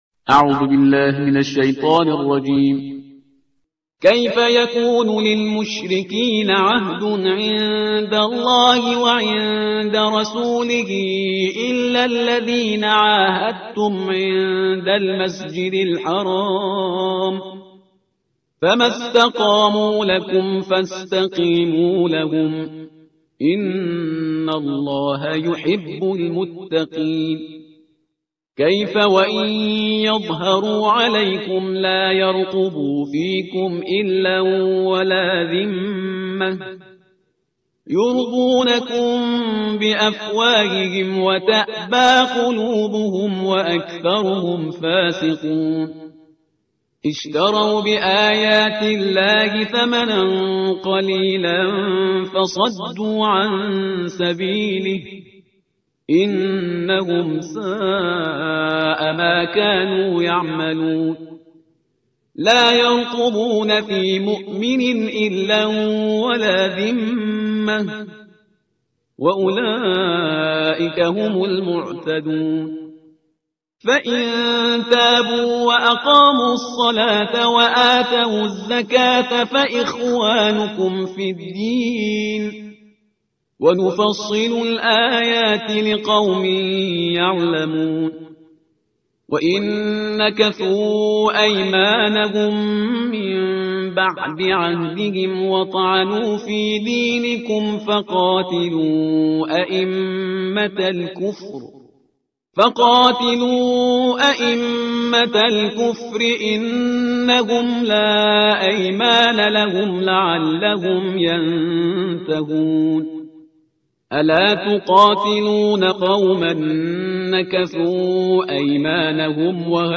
ترتیل صفحه 188 قرآن